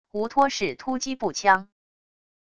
无托式突击步枪wav音频